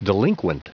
Prononciation du mot delinquent en anglais (fichier audio)
Prononciation du mot : delinquent